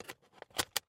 Звуки дисковода
Звук компакт диска в приводе